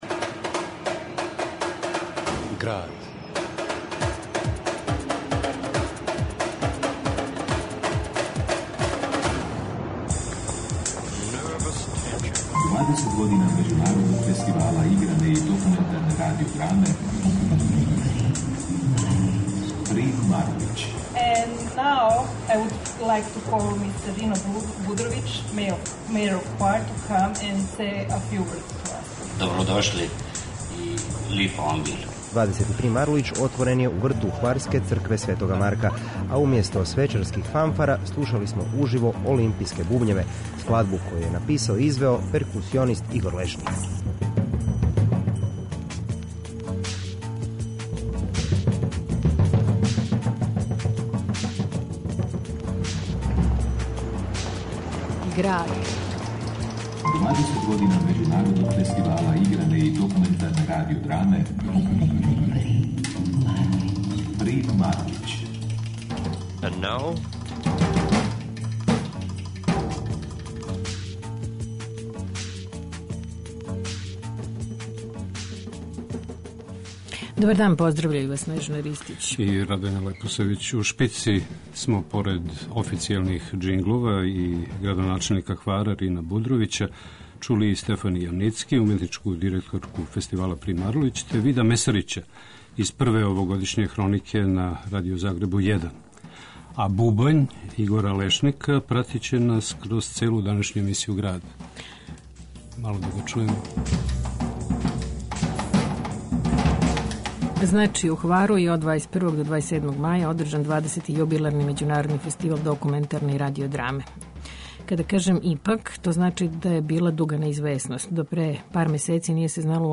У Граду , документарна прича о фестивалу. Инсерти из награђених фичера и драма, разговори са учесницима, коментари, атмосфера...